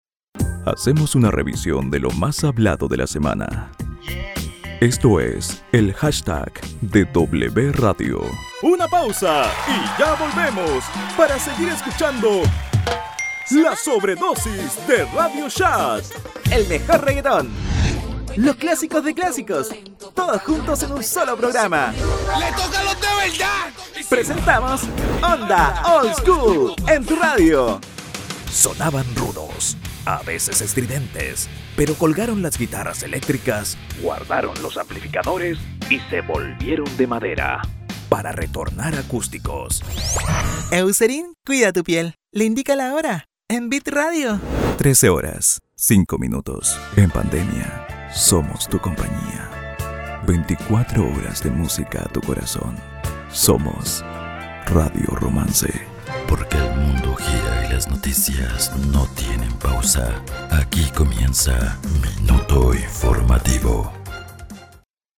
Imagens de rádio
Microfone Neumann TLM 103
Estúdio doméstico
Jovem adulto